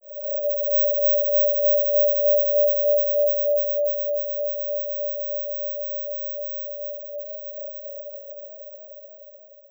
ghostEnemy.wav